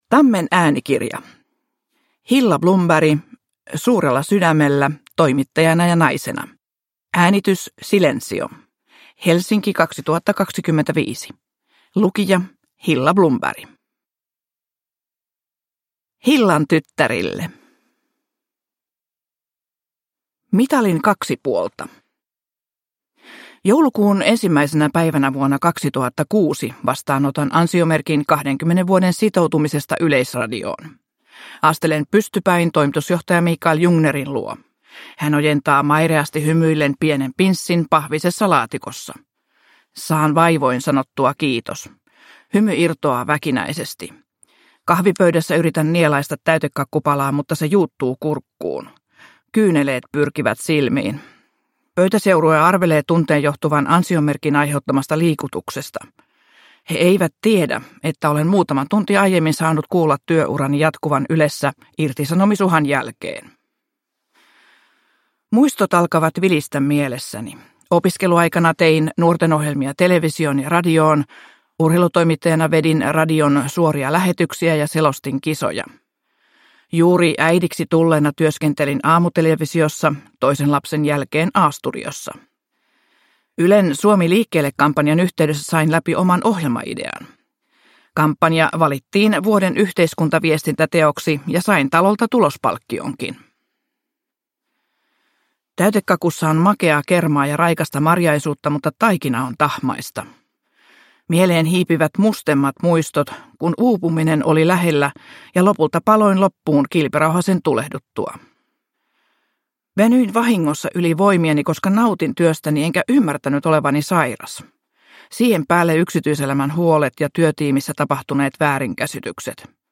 Suurella sydämellä – Ljudbok